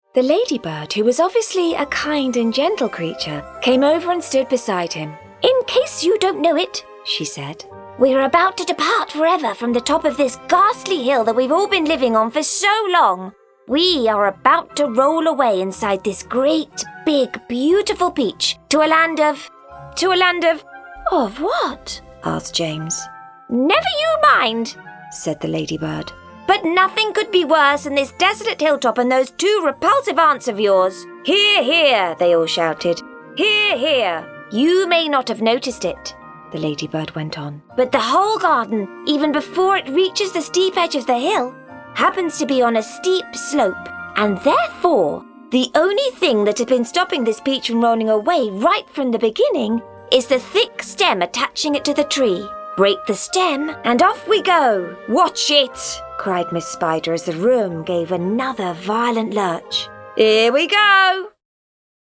A lively British female voice
Animation
British general
Middle Aged